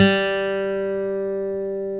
Index of /90_sSampleCDs/Roland - Rhythm Section/GTR_Steel String/GTR_ 6 String
GTR 6 STR G4.wav